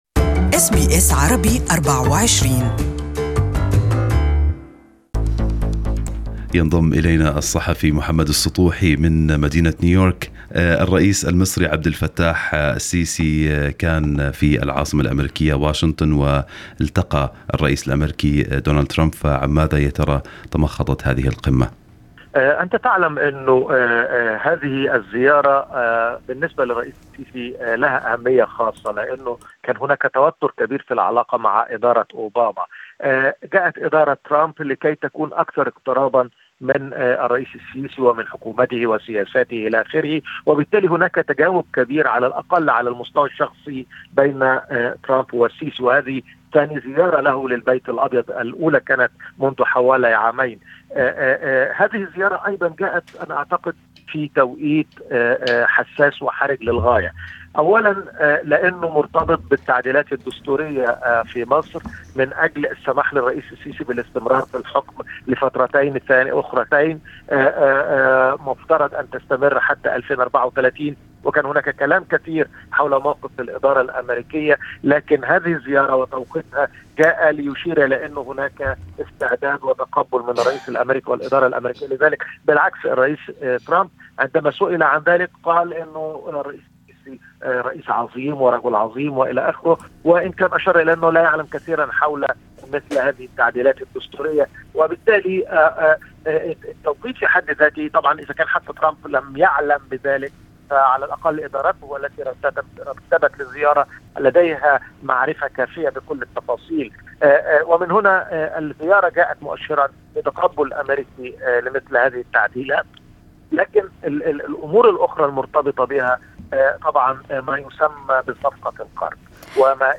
Listen to the full report from New York in Arabic above